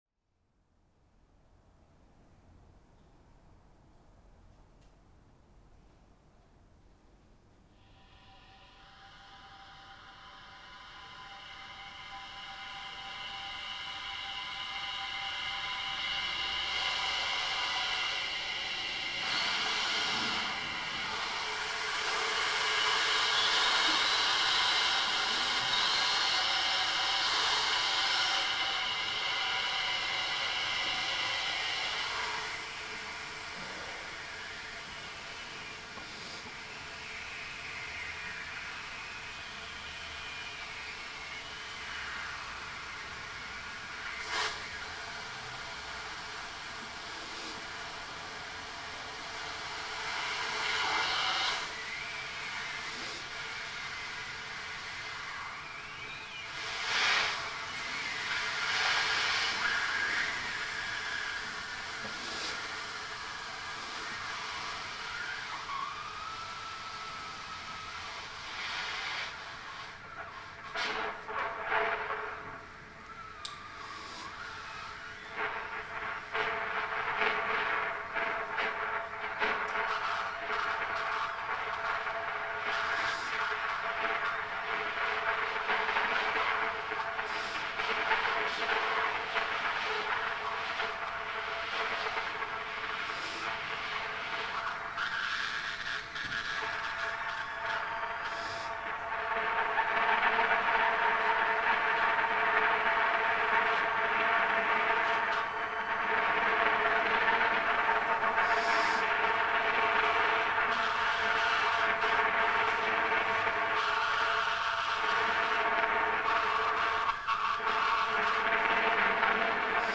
improvised and contemporary music
Trumpet - Solo Improvisation